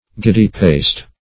Giddy-paced \Gid"dy-paced`\, a. Moving irregularly; flighty; fickle.